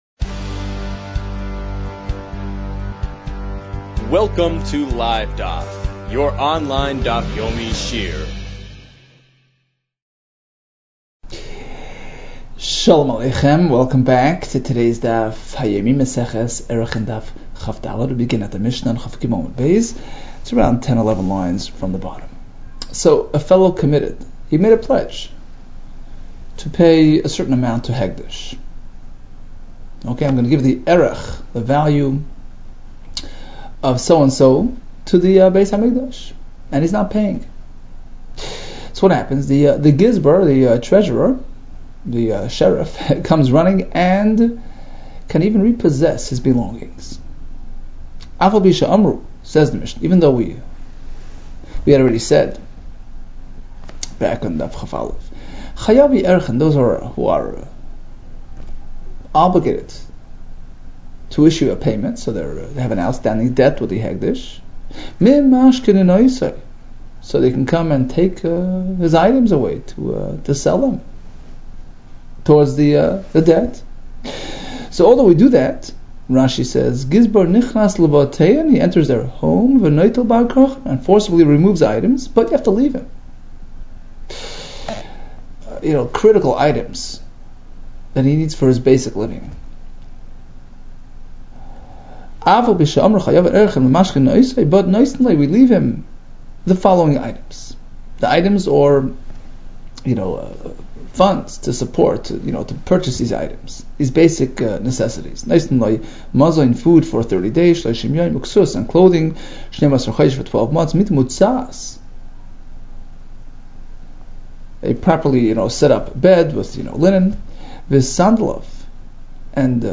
Arachin 23 - ערכין כג | Daf Yomi Online Shiur | Livedaf